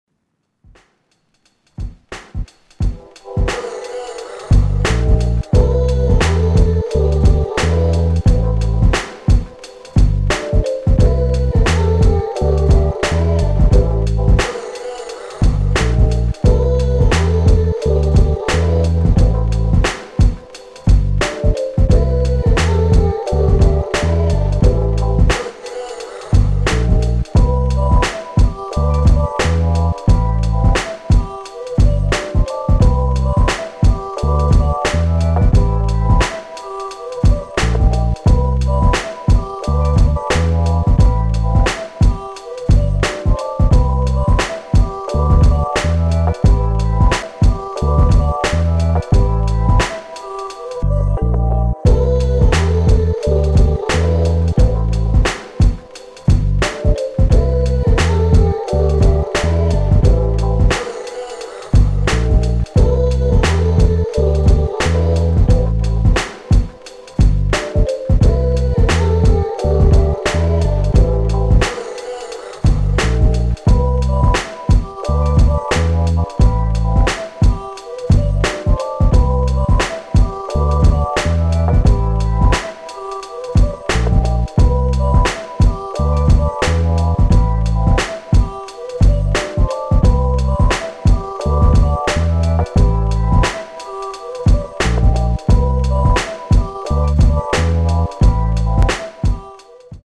Hip Hop, R&B, 90s
E Minor